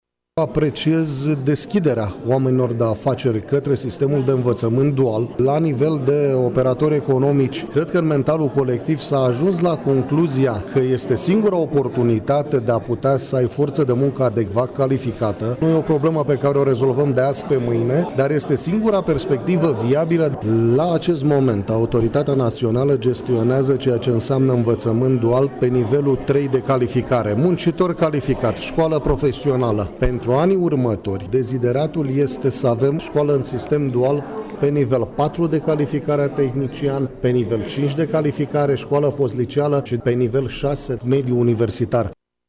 La semnarea protocolului de la Camera de Comerț Mureș au fost prezenți mai mulți patroni și manageri de firme, care au subliniat că e nevoie de o schimbare de mentalitate în ce privește învățământul românesc: